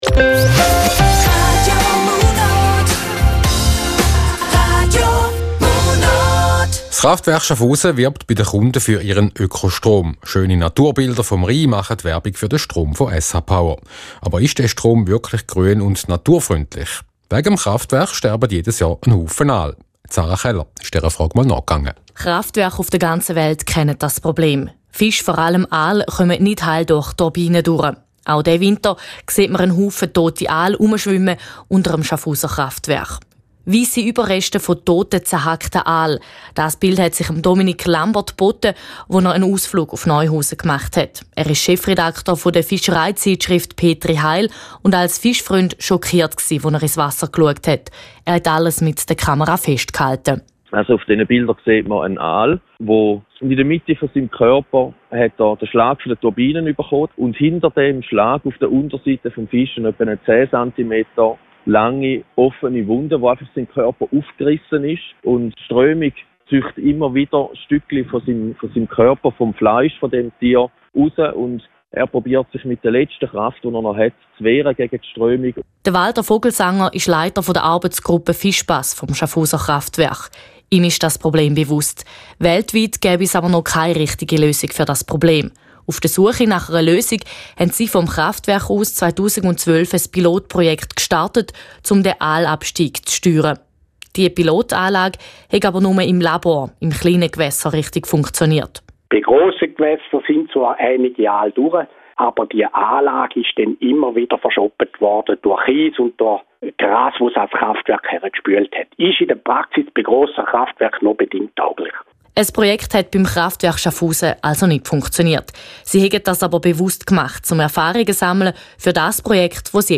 Bericht)